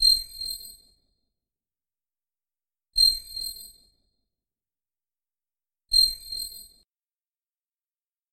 Звуки радара